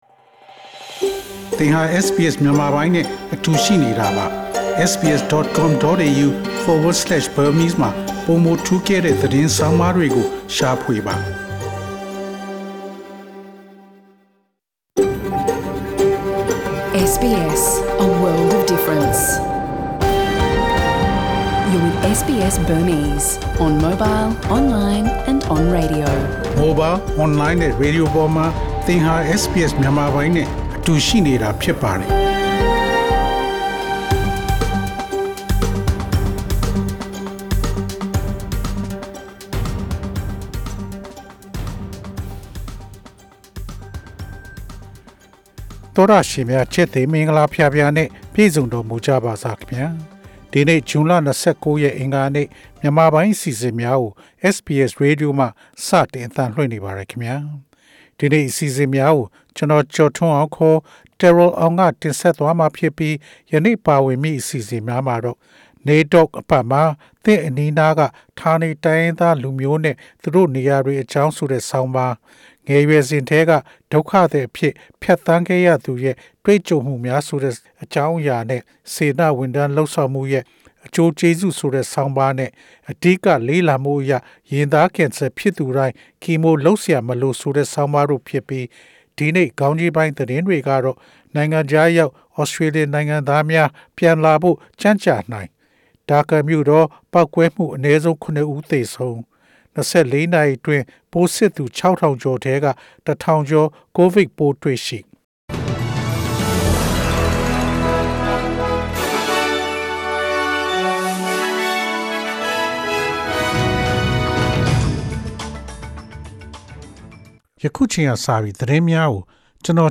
SBS မြန်မာပိုင်း အစီအစဉ် ပေါ့ကတ်စ် သတင်းများ။